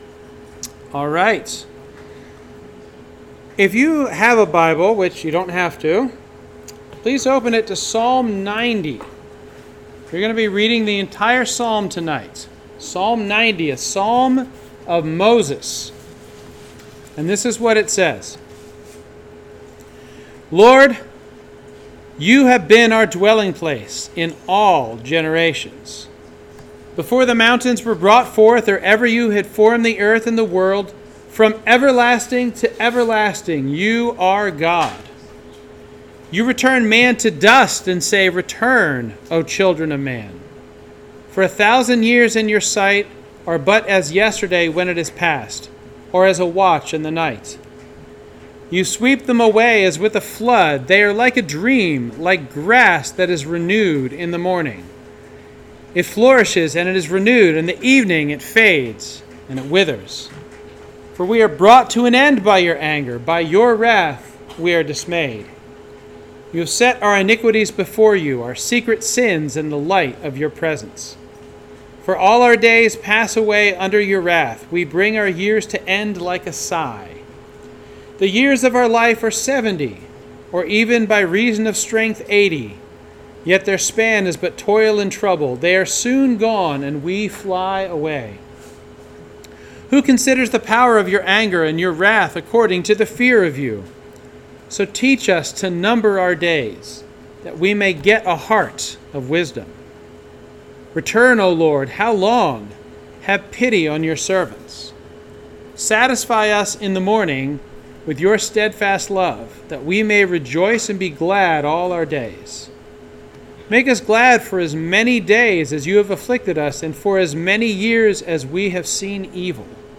2025 Stronghold Sermon Series #1: Intro to Time